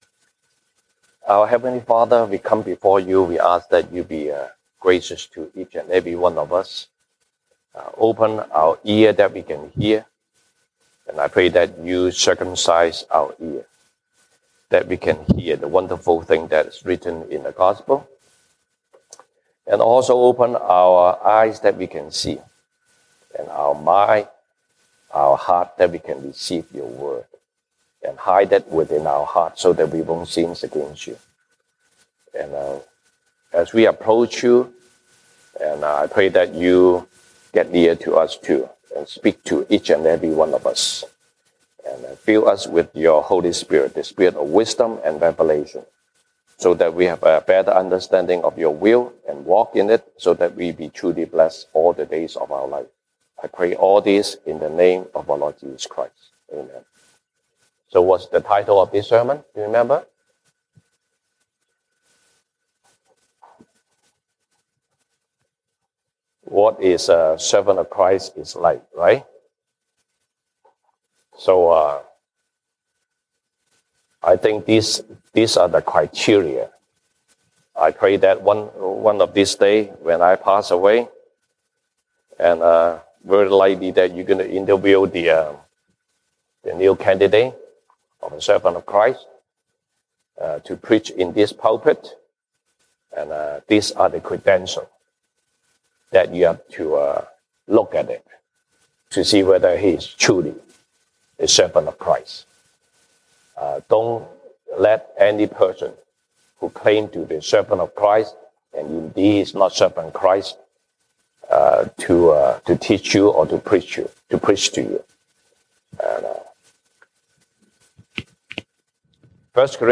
西堂證道 (英語) Sunday Service English: What a servant of Christ is like?